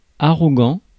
Ääntäminen
IPA: [a.ʁɔ.ɡɑ̃]